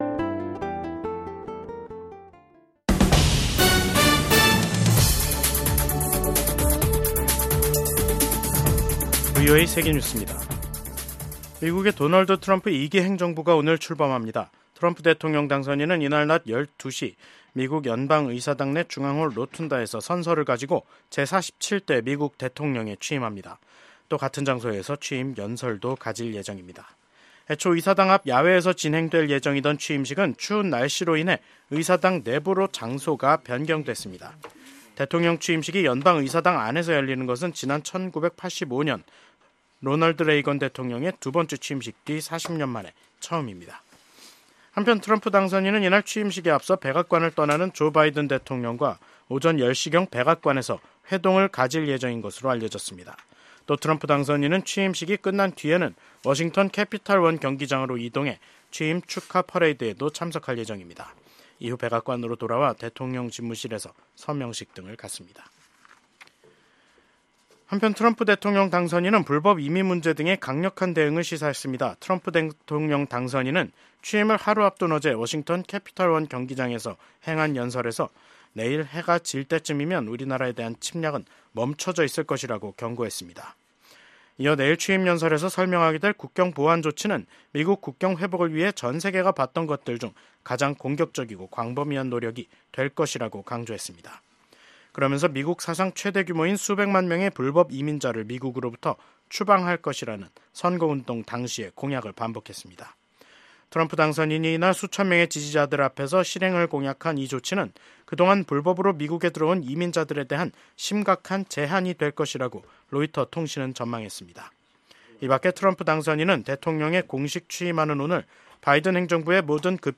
VOA 한국어 간판 뉴스 프로그램 '뉴스 투데이', 2025년 1월 20일 3부 방송입니다. 미국의 제47대 도널드 트럼프 대통령의 취임식 날입니다. VOA한국어 방송의 뉴스투데이는 오늘, 취임식 특집 방송으로 진행합니다.